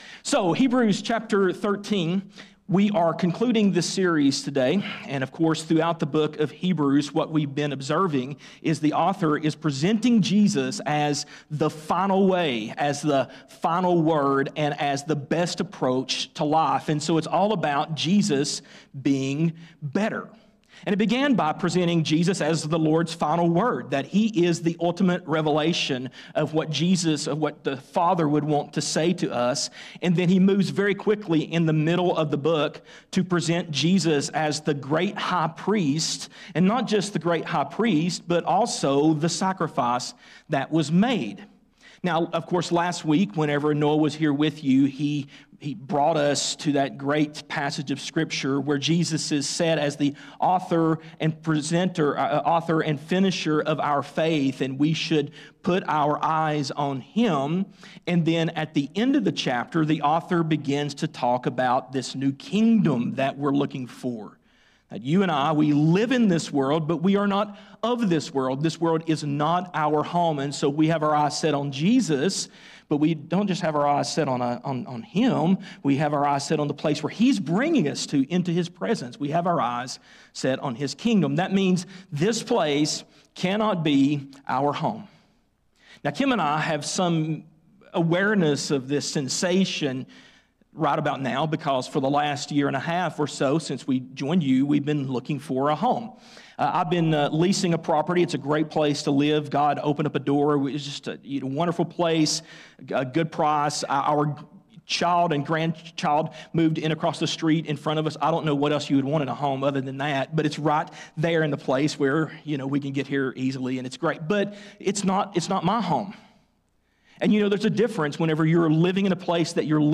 Sermons | Christiansburg Baptist Church | Christiansburg, VA